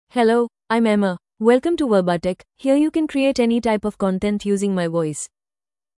Emma — Female English (India) AI Voice | TTS, Voice Cloning & Video | Verbatik AI
FemaleEnglish (India)
Emma is a female AI voice for English (India).
Voice sample
Listen to Emma's female English voice.
Emma delivers clear pronunciation with authentic India English intonation, making your content sound professionally produced.